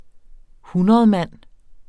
hundredmand substantiv, fælleskøn Bøjning -en, ..mænd, ..mændene Udtale [ ˈhunʌð- ] Betydninger pengeseddel med værdien 100 kr.